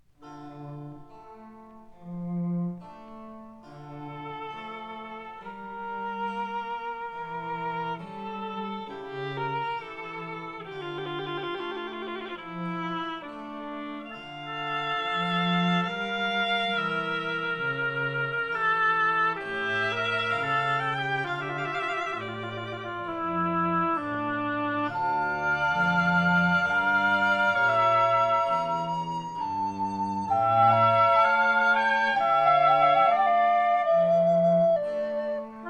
trumpet
recorder
violin
harpsichord
1960 stereo recording made by